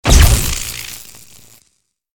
LB_capacitor_discharge_3.ogg